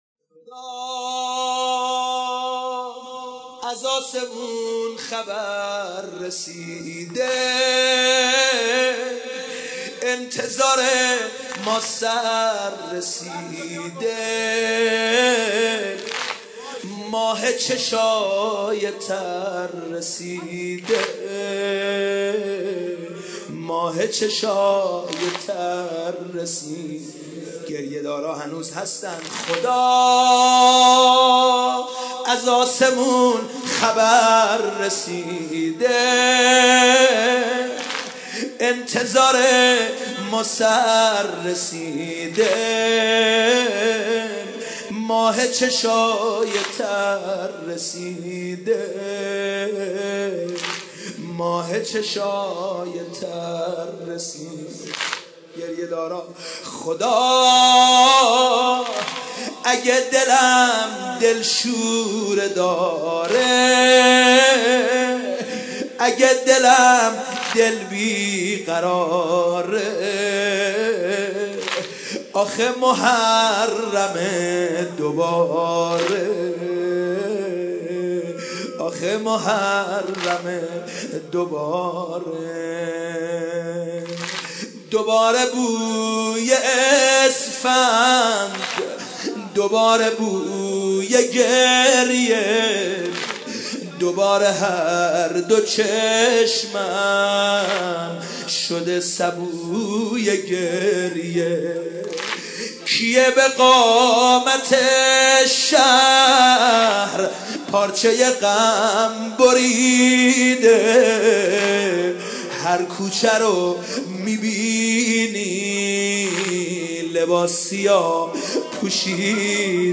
دسته : سنتی ایرانی